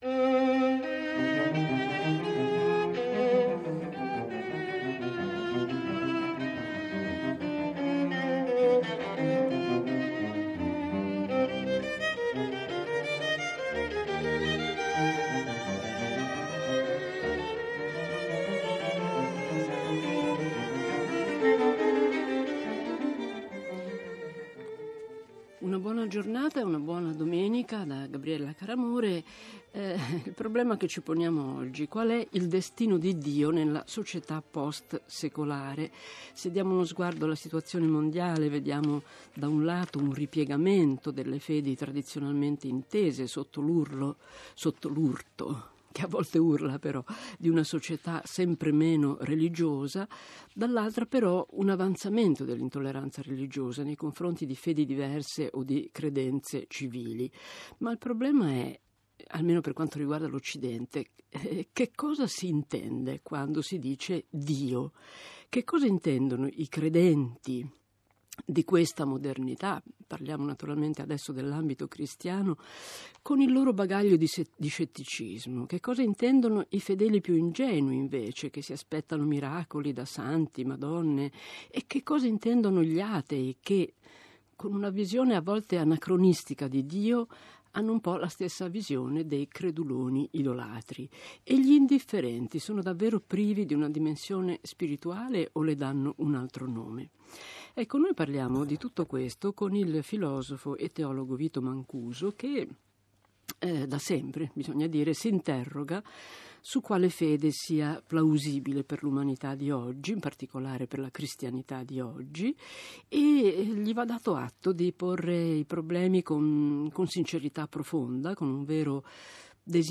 da "uomini e profeti" trasmissione di radio tre.